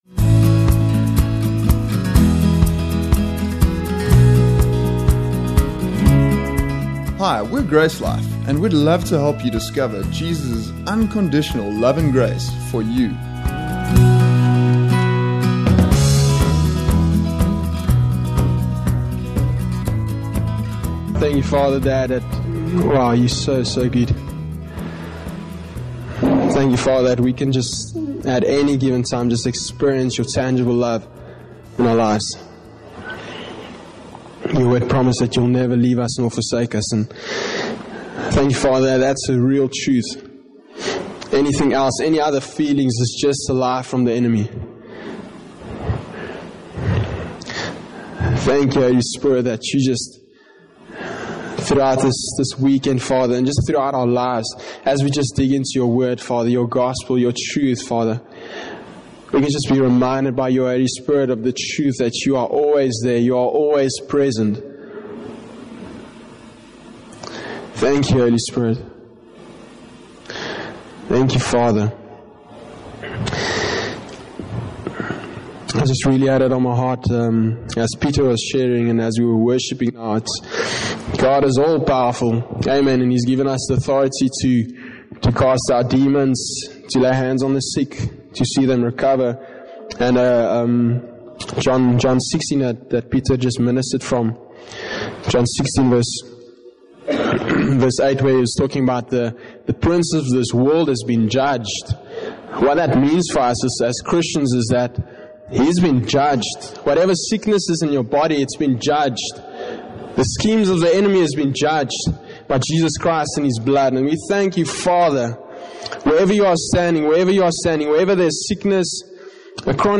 The Freedom Conference: A Life Transformed by Surrendering